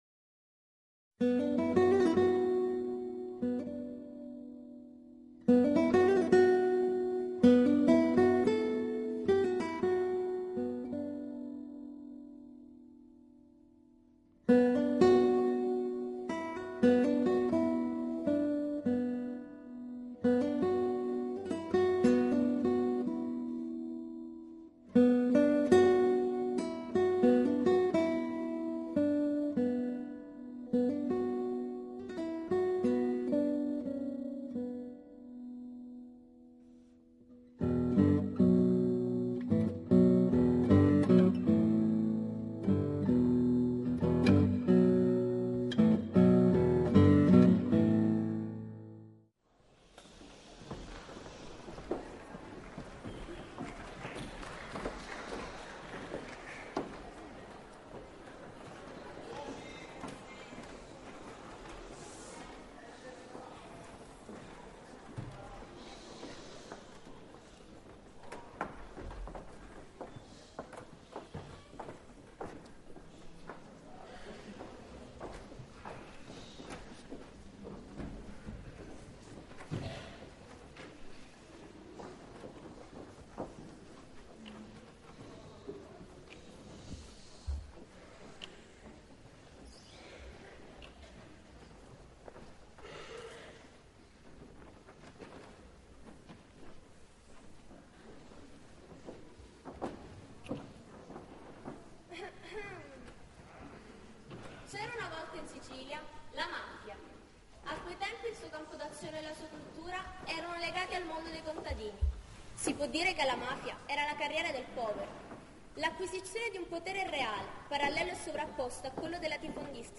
Teatro Biondo - Palermo